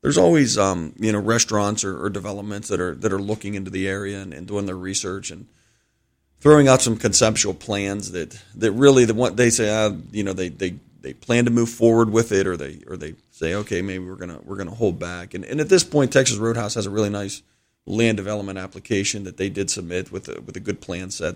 in an interview on Indiana in the Morning